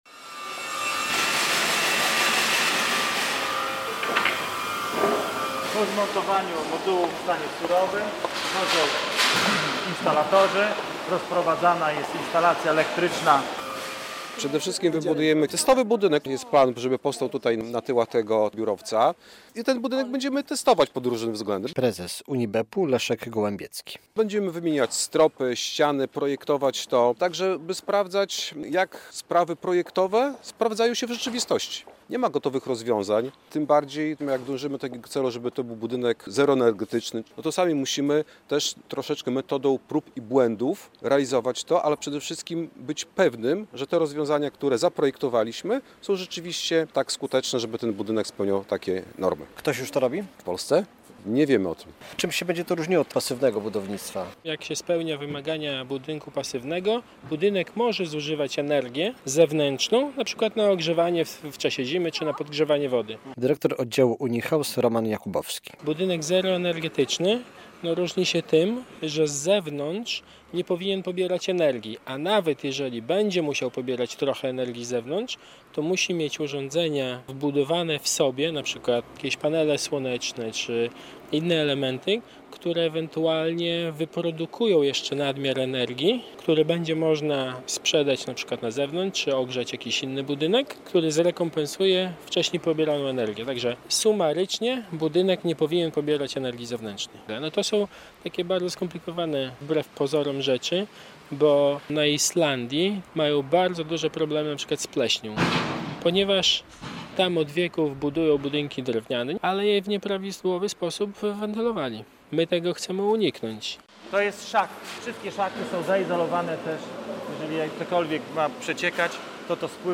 Jak mówi wicemarszałek województwa podlaskiego Maciej Żywno - projekt idealnie wpisuje się w strategię rozwoju regionu, który stawia na nowoczesne technologie z wykorzystaniem zielonej energii.